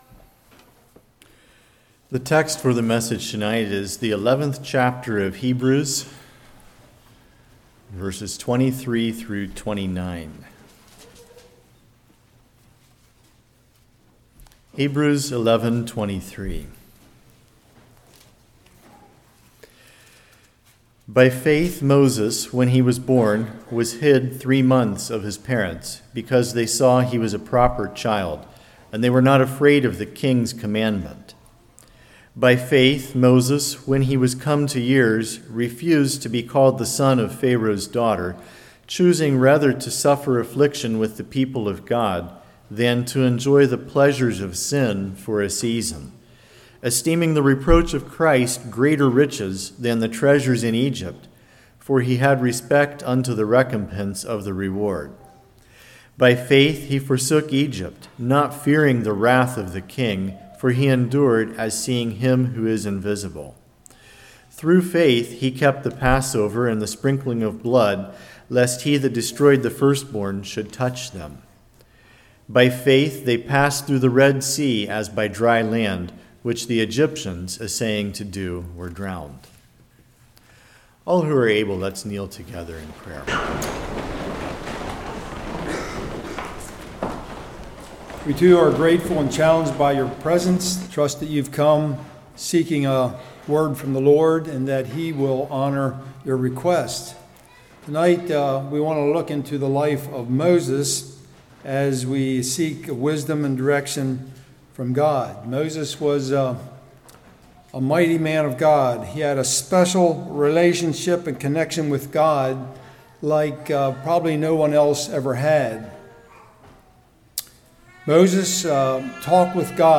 Hebrews 11:23-29 Service Type: Revival Recognize there is things in life that you do not have control of